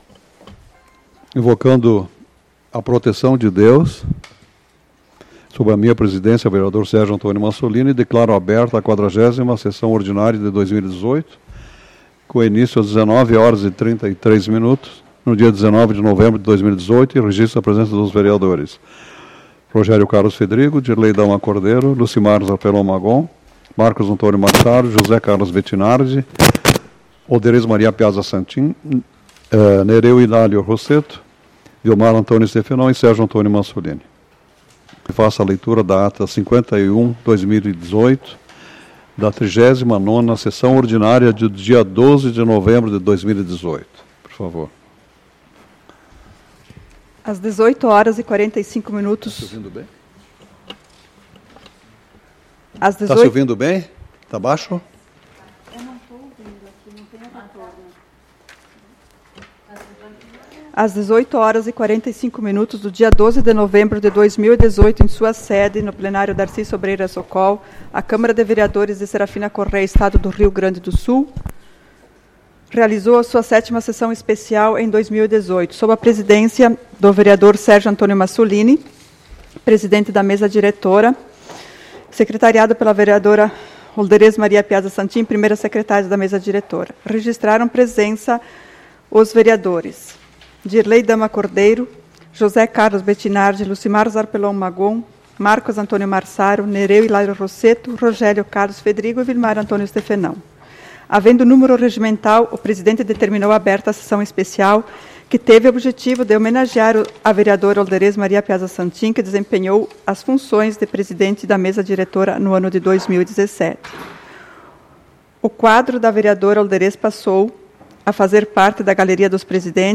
SAPL - Câmara de Vereadores de Serafina Corrêa - RS